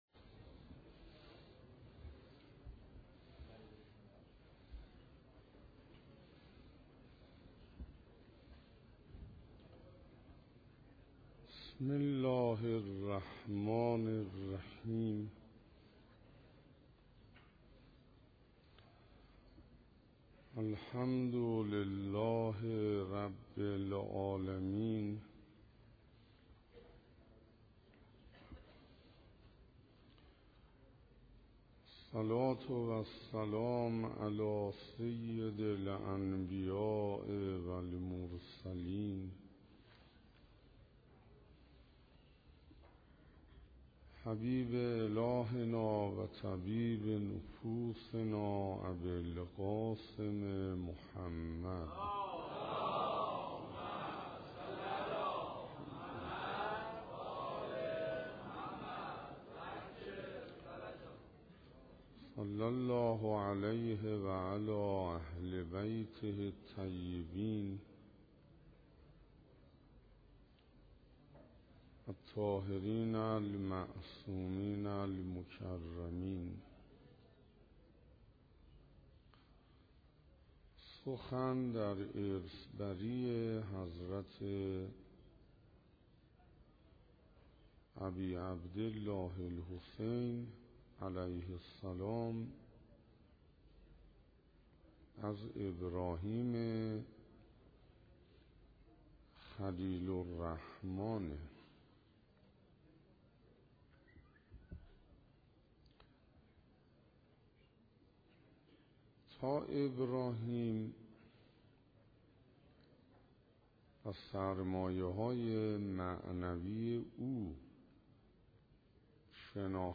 شرح زیارت وارث - سخنراني ششم - محرم 1435 - مسجد امیر -